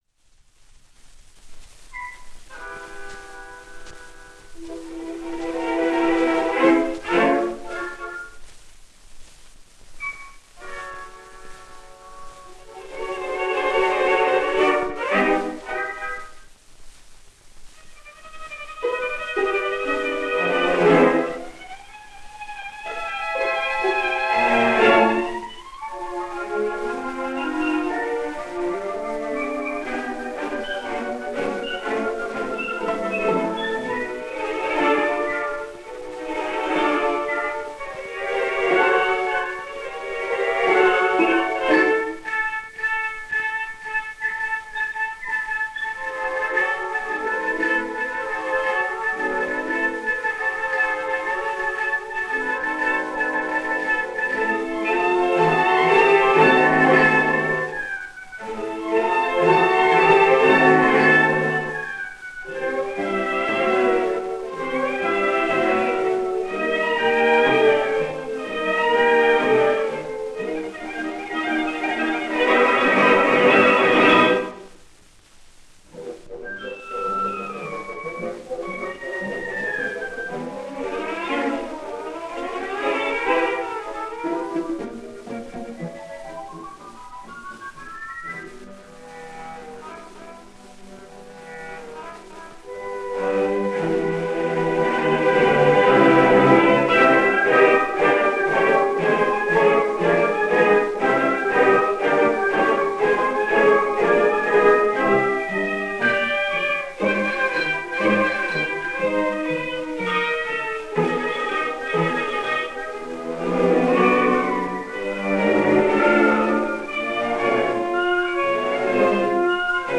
А.К.Лядов. Кикимора, народное сказание - Оркестр Большого театра СССР, дирижёр В.Небольсин